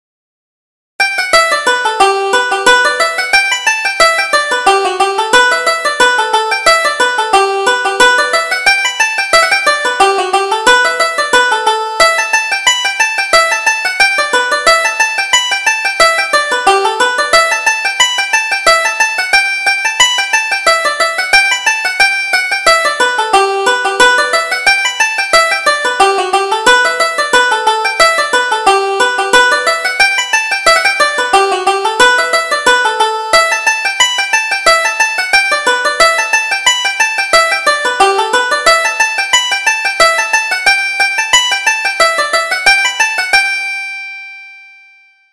Reel: O'Reilly's Greyhound